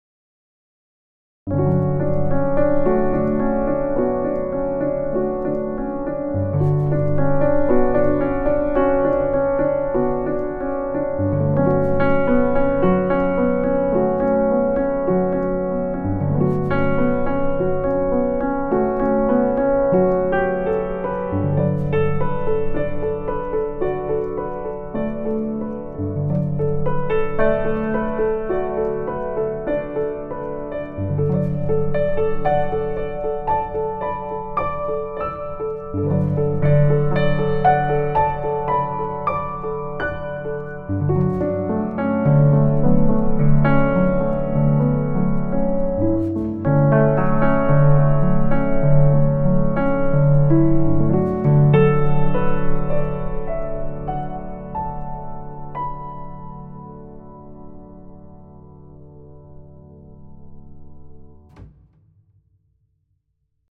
GM7の和音で、雰囲気よくコンテンポラリー
リズムも拍子も関係なくひたすらGM7を自由に。メロディはト長調のスケール音を使ってますが、厳密ではなく。シャープイレブンの音を入れると、少し物悲しげになります。
GM7: Contemporary-style, with atmosphere
Ignore rhythm and meter and just play freely on GM7.
Adding a sharp 11th gives a slightly melancholic feeling.